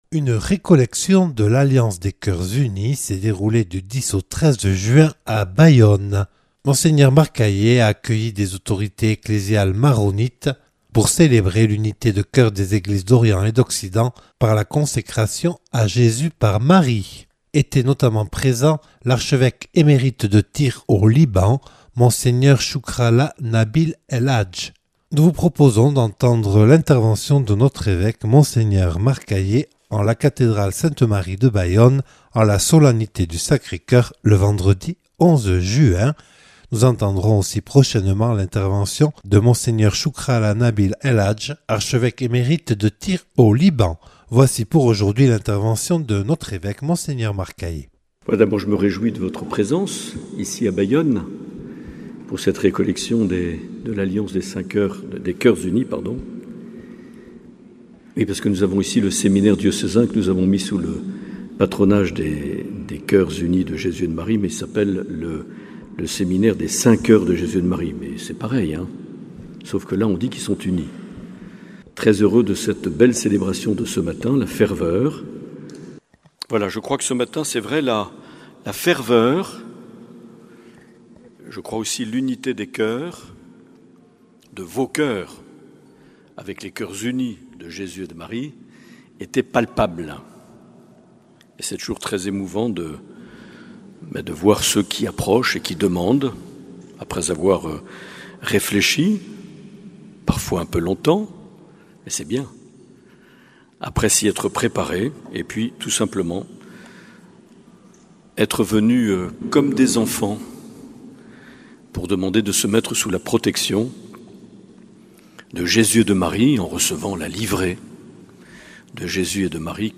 Récollection de l’Alliance des Cœurs Unis le 11 juin 2021 à la cathédrale de Bayonne (1/2)
Intervention de Mgr Marc Aillet.